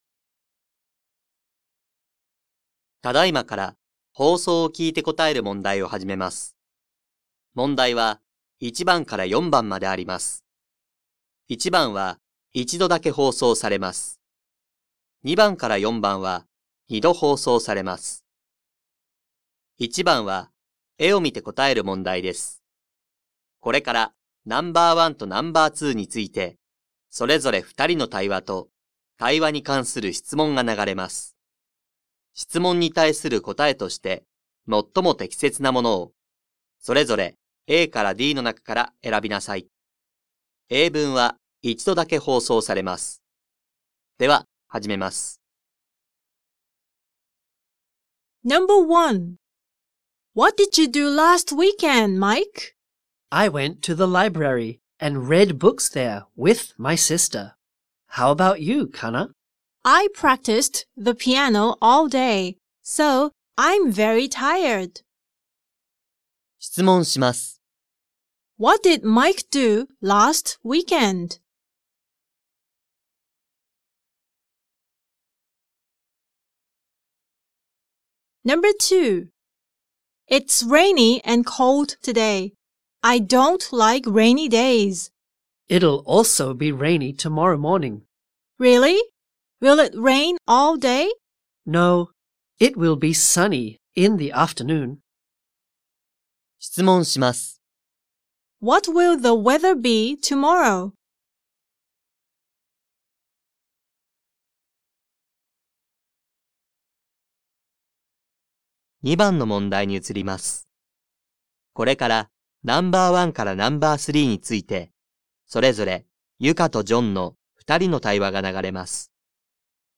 2024年度３年８号英語のリスニングテストの音声